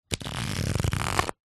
Звуки игральных карт
Звук раздающихся карт nШорох игральных карт nЗвучание карточной колоды